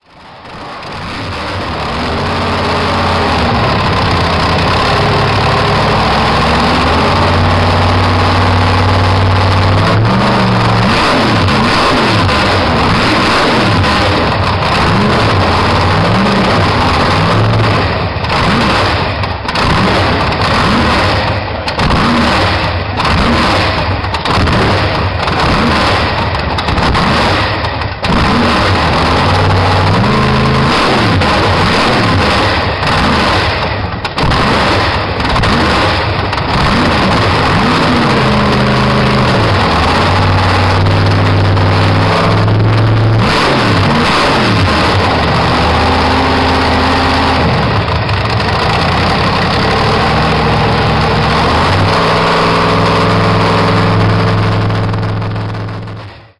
10h de moteur de tractopelle
TractopelleConcerto.mp3